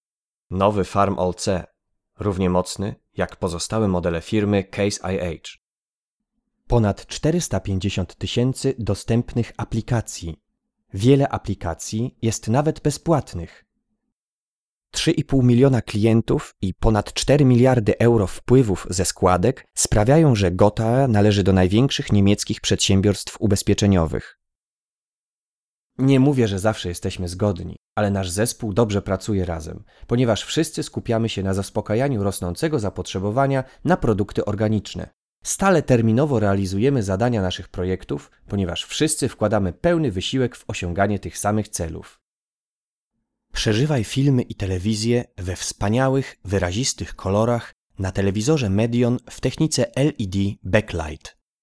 Flexible Tonfärbung.
Sprechprobe: Industrie (Muttersprache):